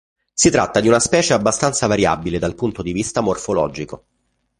/vaˈrja.bi.le/